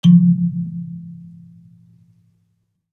kalimba_bass-F2-mf.wav